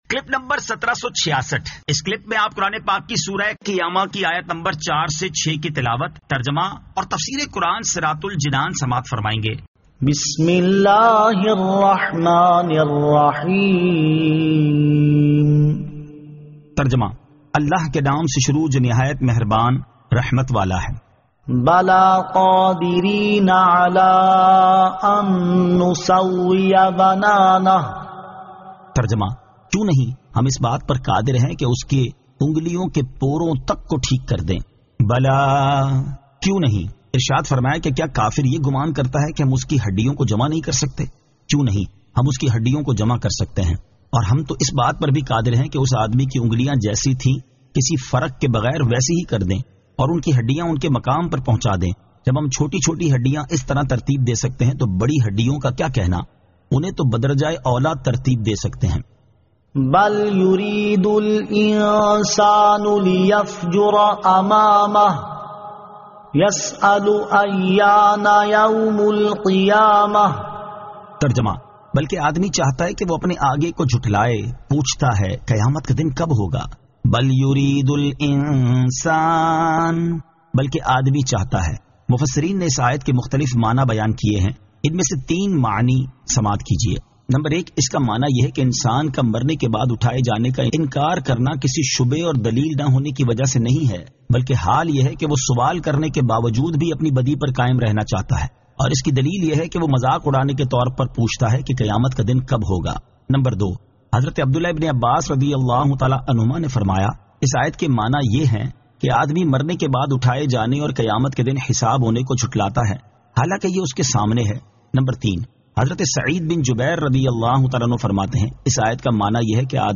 Surah Al-Qiyamah 04 To 06 Tilawat , Tarjama , Tafseer